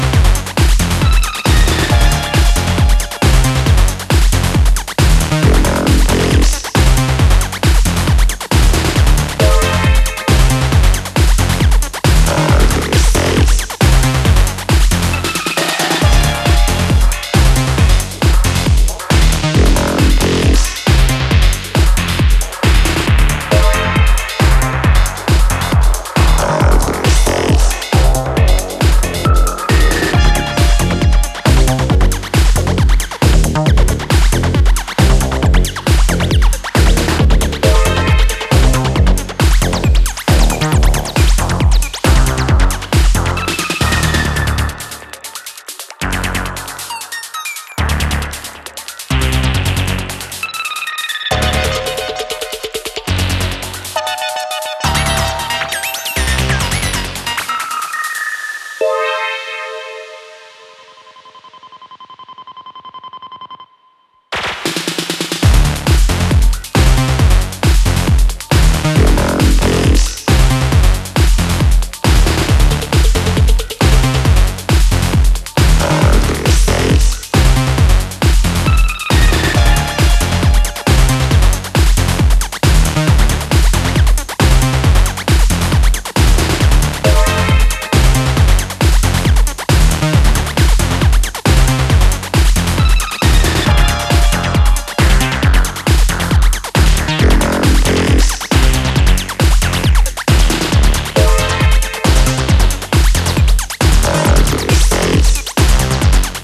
Four solid electro cuts dominate